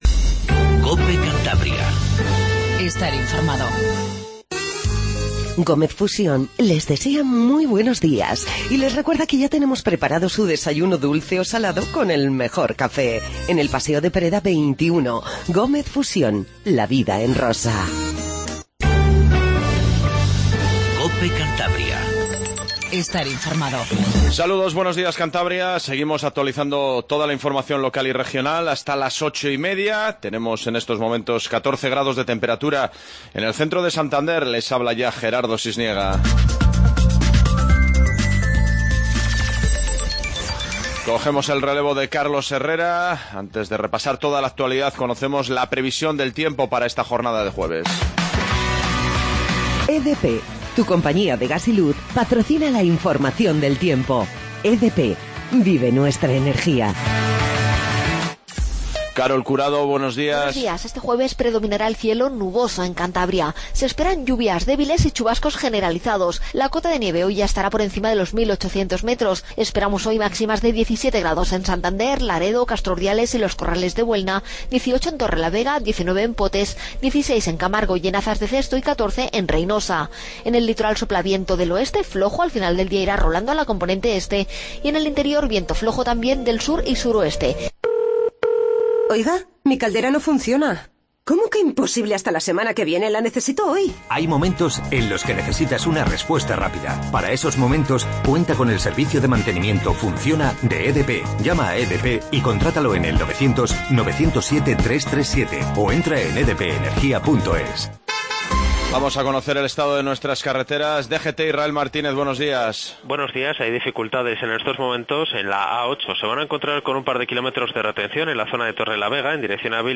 INFORMATIVO MATINAL 07:50